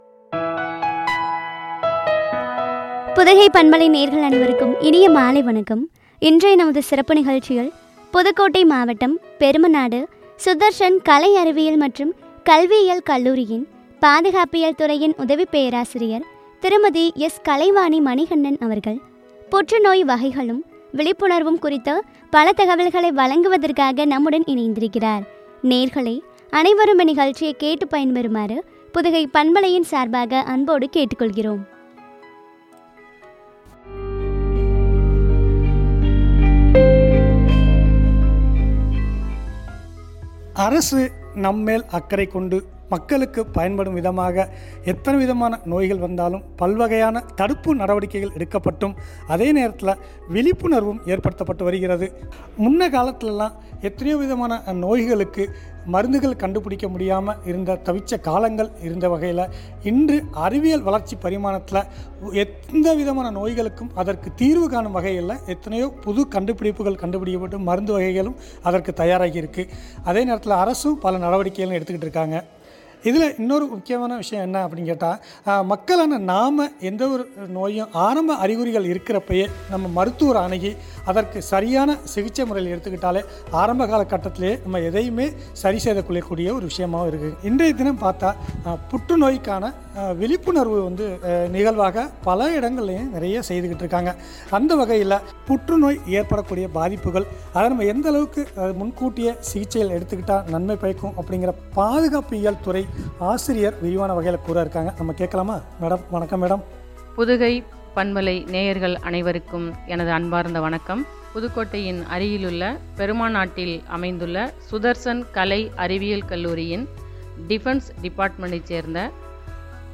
புற்றுநோய் வகைகளும், விழிப்புணர்வும் பற்றிய உரை.